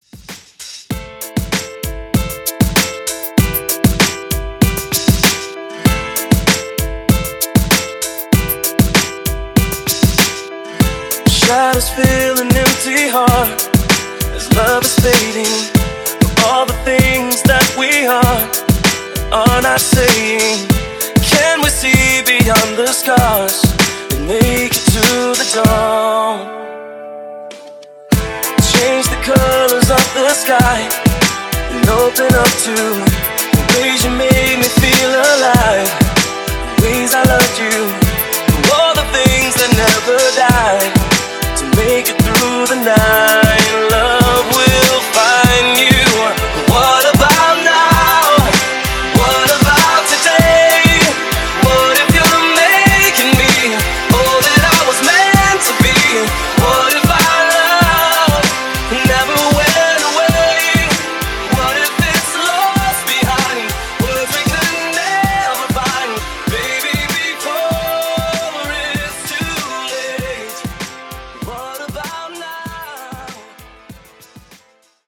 Genres: ACAPELLAS , MASHUPS , TOP40
Clean BPM: 126 Time